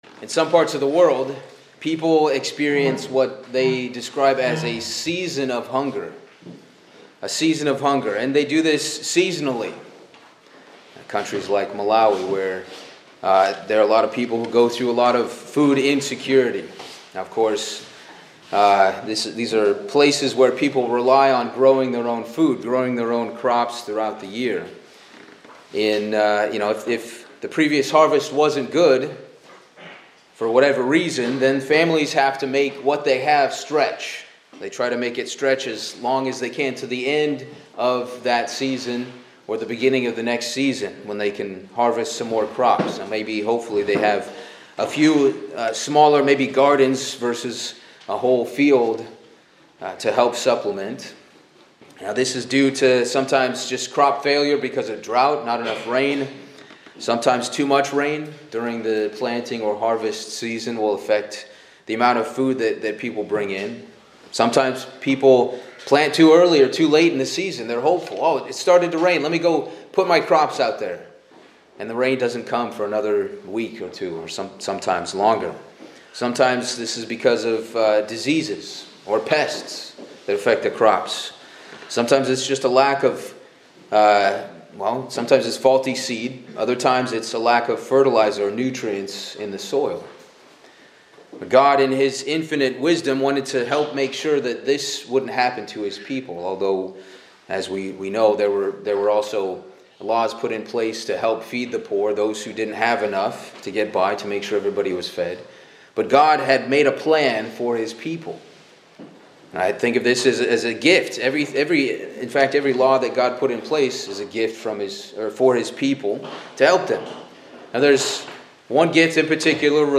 The sermon explores the biblical concepts of atonement and liberty, focusing on the significance of the land Sabbath and the Year of Jubilee as described in Leviticus. It emphasizes how these laws were gifts from God to ensure rest, renewal, and provision for His people, especially the poor and oppressed.